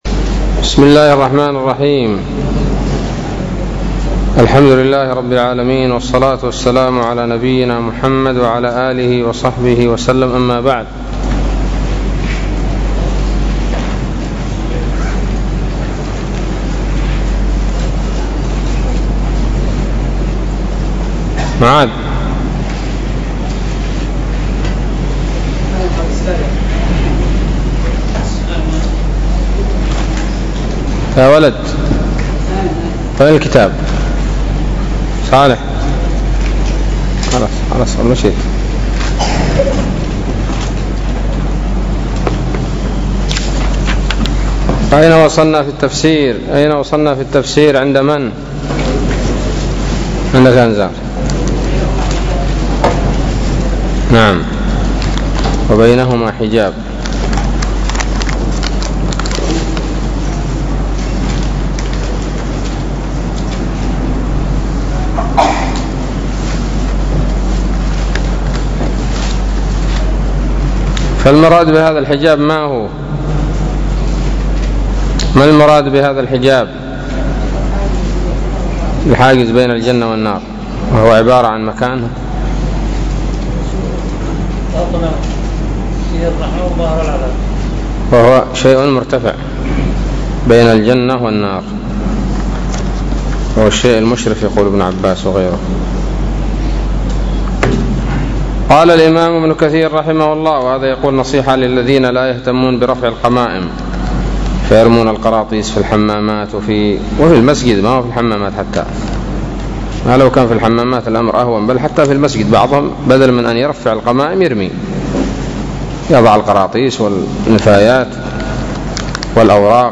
الدرس التاسع عشر من سورة الأعراف من تفسير ابن كثير رحمه الله تعالى